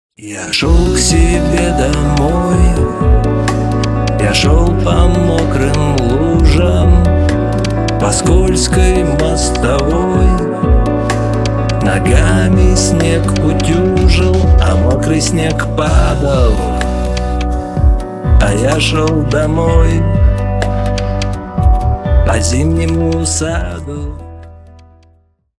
Сразу скажу, что уровень записи неплохой. Вокалисты тоже не с улицы.